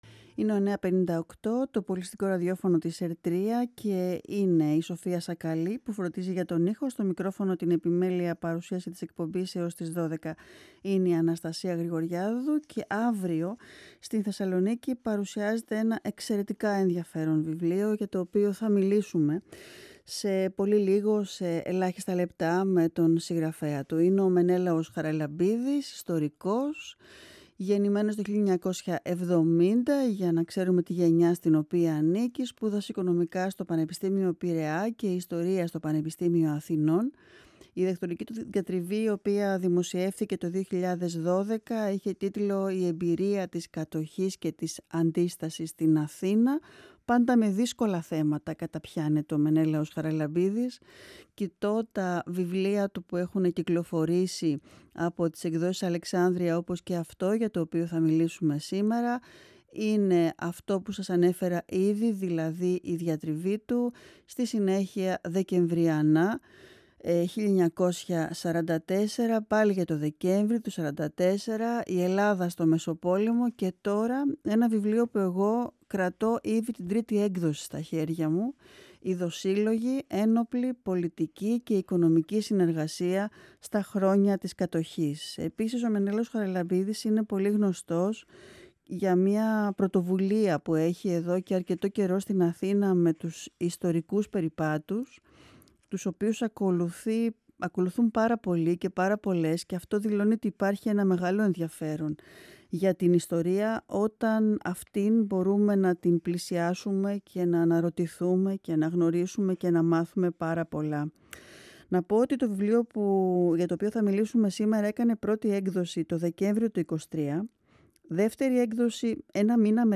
Σ υνέντευξη
Η συνέντευξη πραγματοποιήθηκε την Τρίτη 5 / 3 /2024 εκπομπή “καλημέρα” στον 9,58fm της ΕΡΤ3